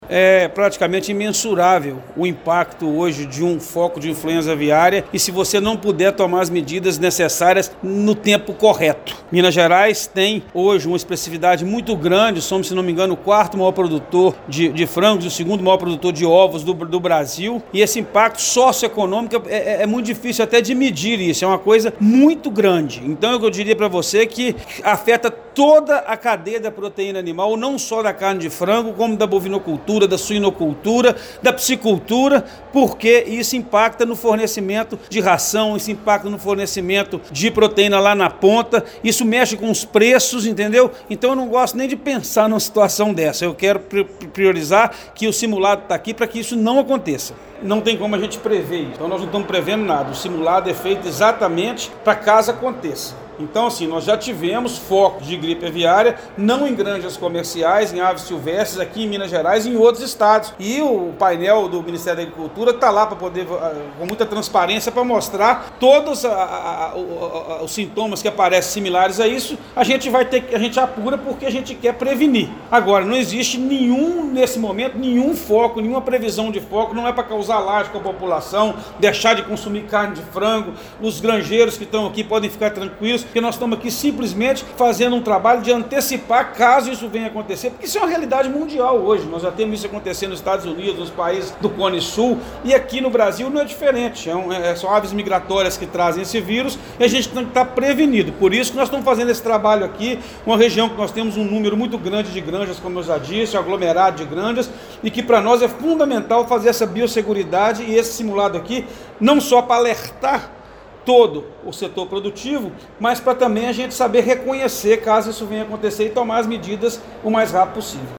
Durante a abertura, o secretário de Estado de Agricultura, Pecuária e Abastecimento, Thales Fernandes, enfatizou que o treinamento é essencial para demonstrar a eficiência do sistema mineiro.